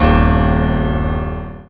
55y-pno06-a#2.aif